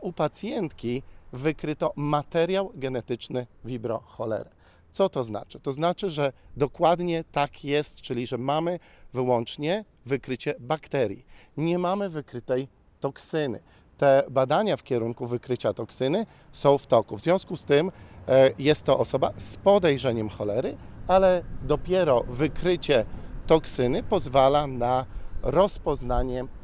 Powiedział dziennikarzom Prof. Miłosz Parczewski – konsultant krajowy ds. zakaźnych ze Szczecina wyjaśnia sytuację pacjentki: